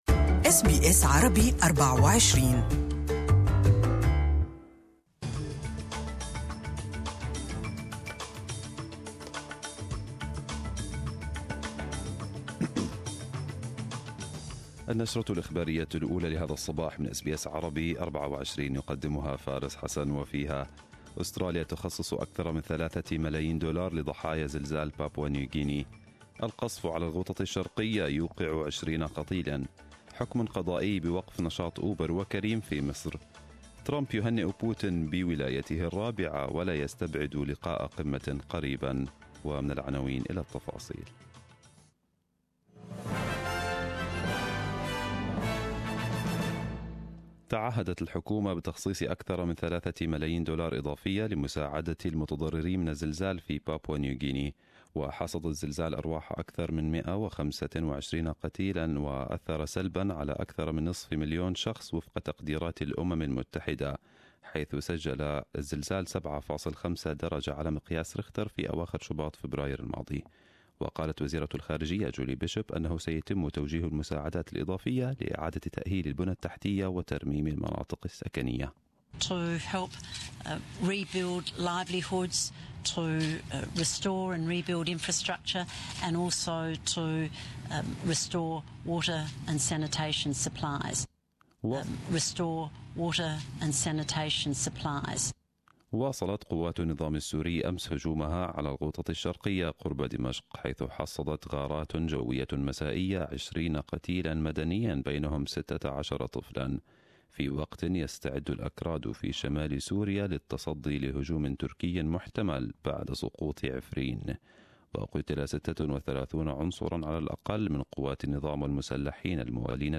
Arabic News Bulletin 21/03/2018